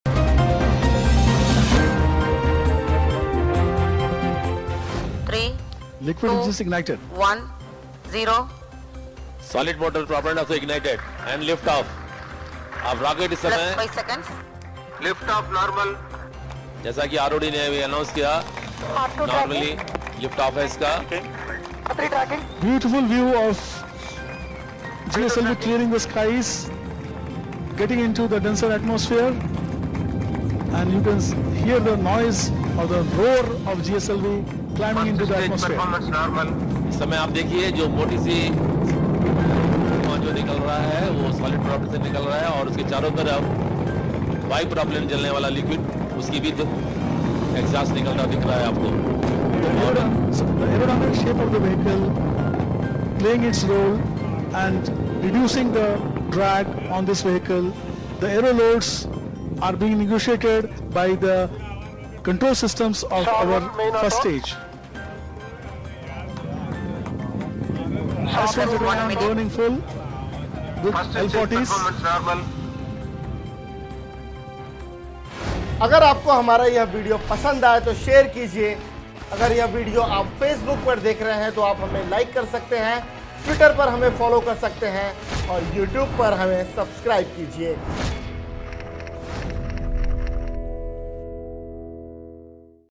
न्यूज़ रिपोर्ट - News Report Hindi / संचार उपग्रह जीसैट-7ए का सफल प्रक्षेपण, वायुसेना के लिए होगा मददगार